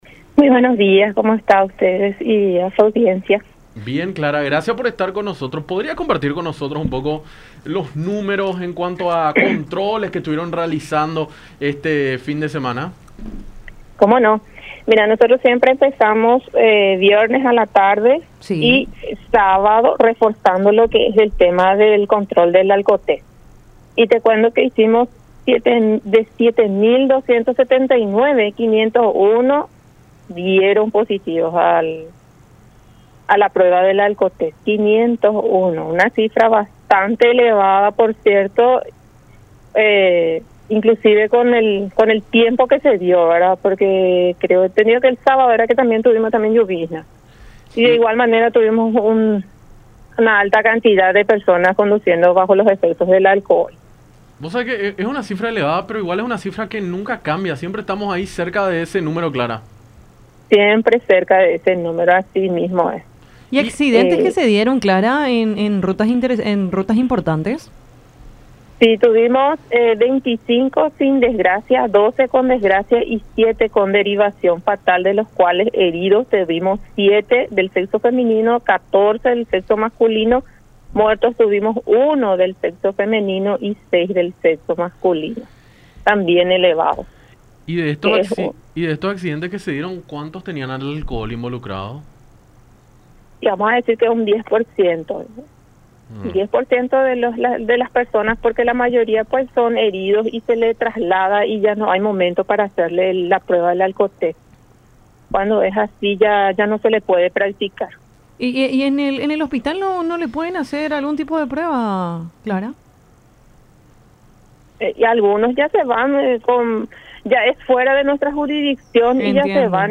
en conversación con Enfoque 800 por La Unión